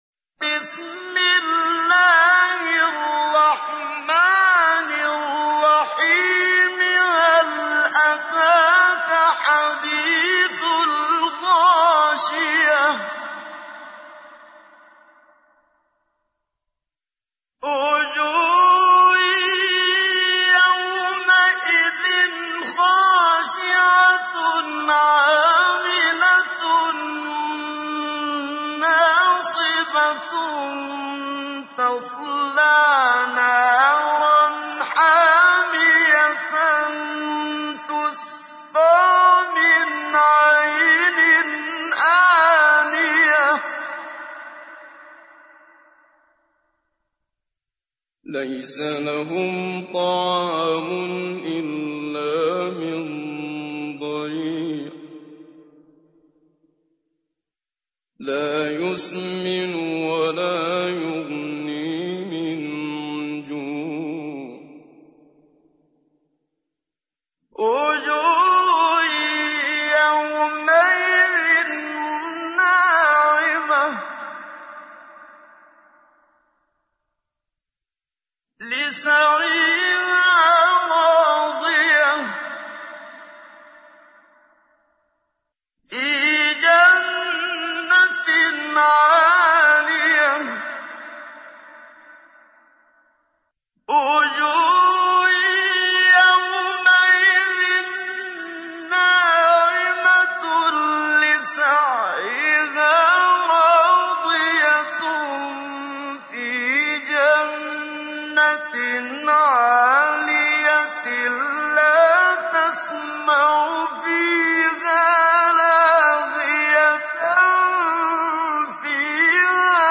تحميل : 88. سورة الغاشية / القارئ محمد صديق المنشاوي / القرآن الكريم / موقع يا حسين